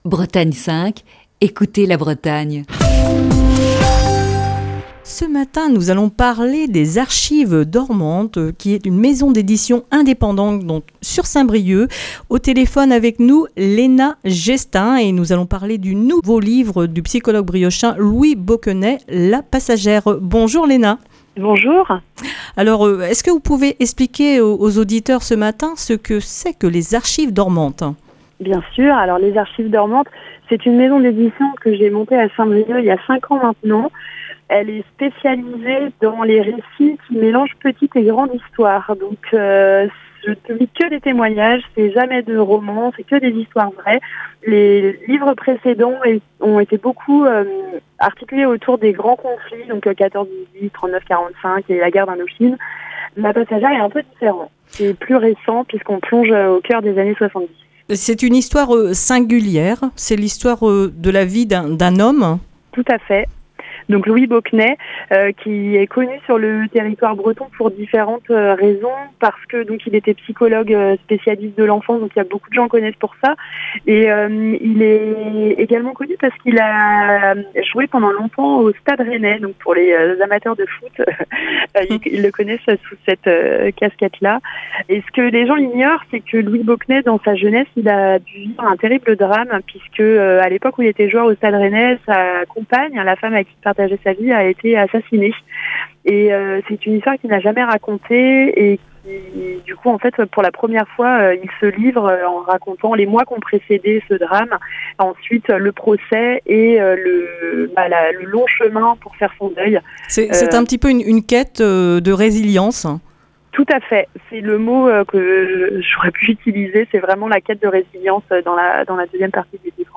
(Émission diffusée le 3 novembre 2020).